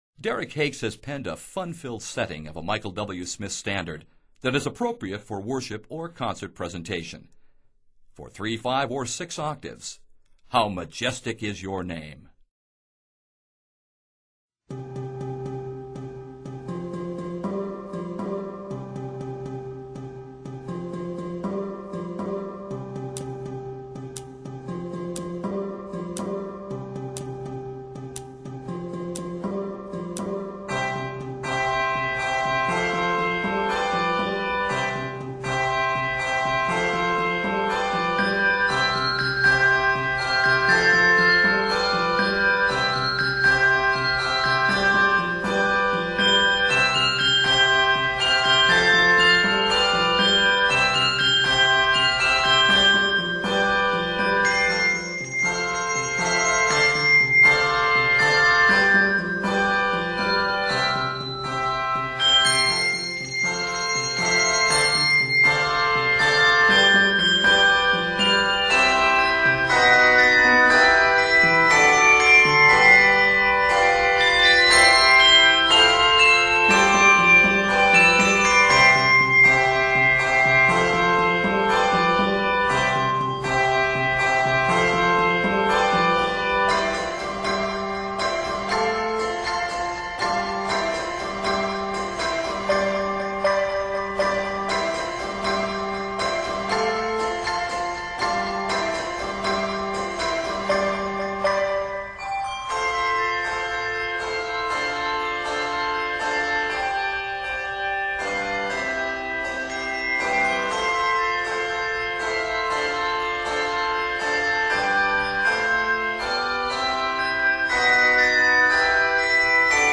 Scored in C Major, it is 90 measures.